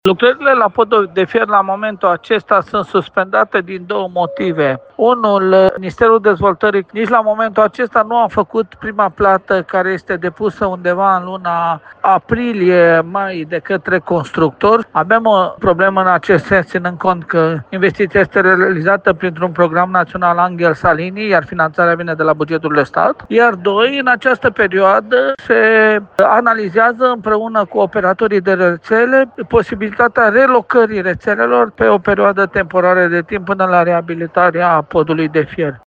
Primarul municipiului, Călin Dobra.